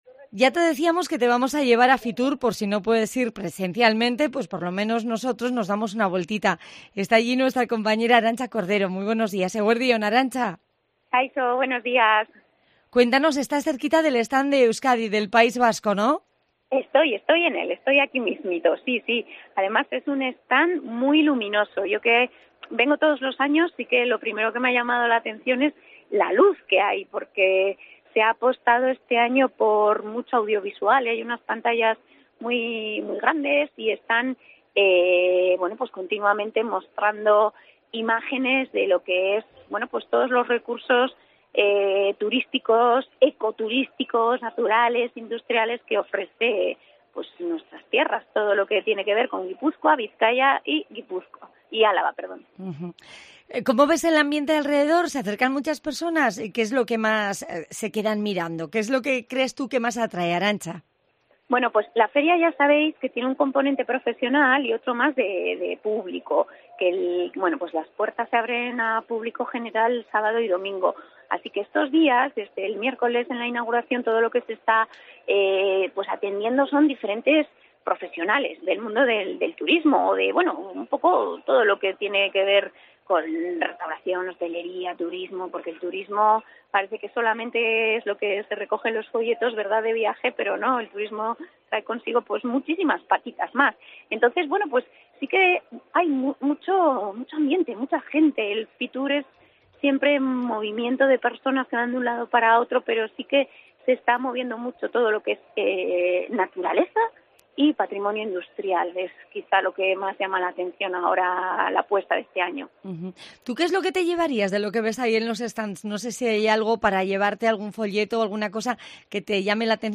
Conexión en directo: nos asomamos al stand de Euskadi en Fitur